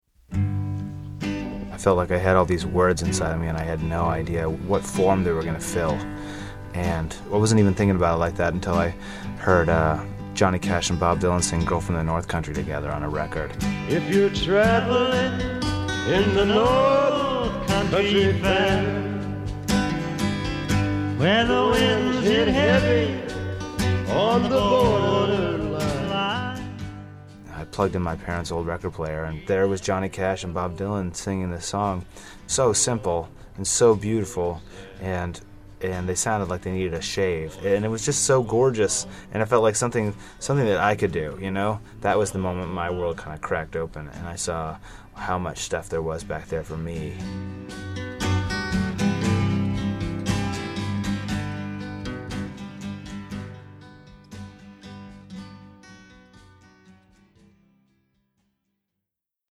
Music Credit: Excerpt of “Girl from the North Country” from the album, Nashville Skyline, written by Bob Dylan and performed by Dylan and Johnny Cash, used courtesy of Sony Music Entertainment and by permission of Special Rider Music (SESAC).
josh-ritter-on-girl-from-north.mp3